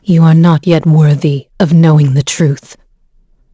Isolet-Vox_Victory.wav